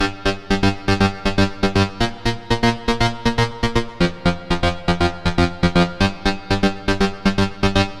loops basses dance 120 - 2
Basse dance 5 F